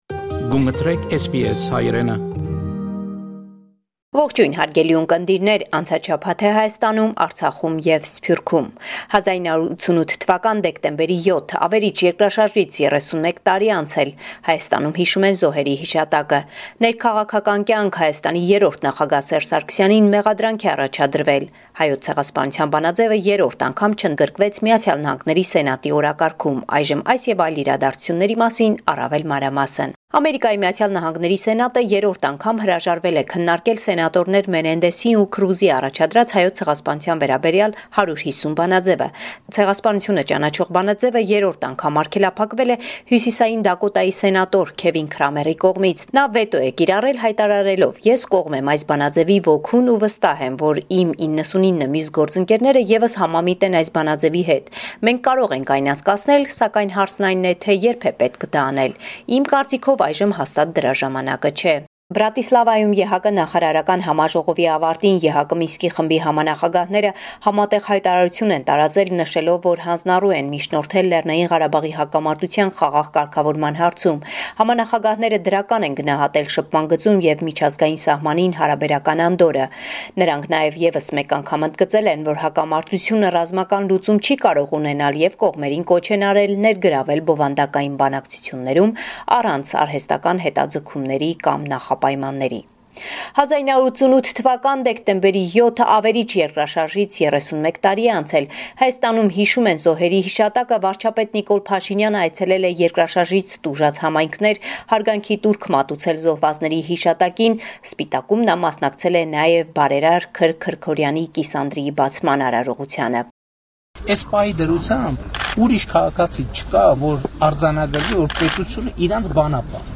Վերջին Լուրերը Հայաստանէն – 10 Դեկտեմբեր 2019